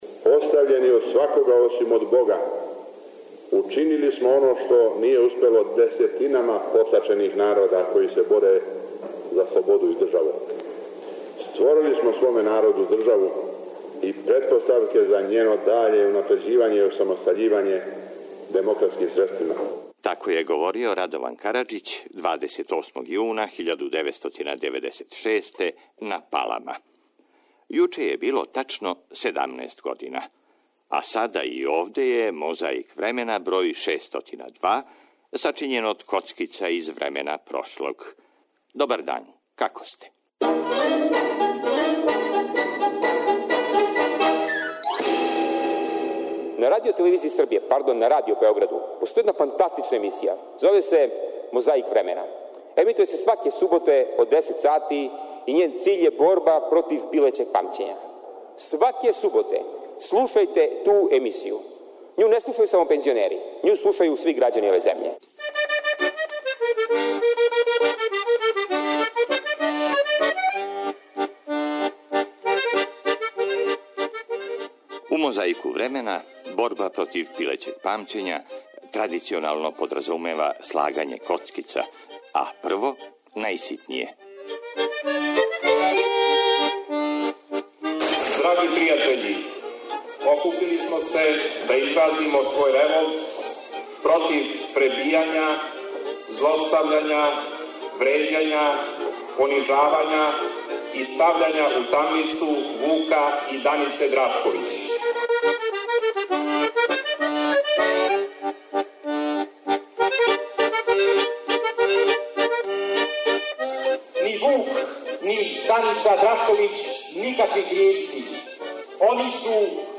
Још једном склапамо коцкице прошлости у мозаик сећања. Присетићемо се кључних догађаја у деценијама иза нас који су се збили крајем јуна и чути радио записе који су те догађаје пратили.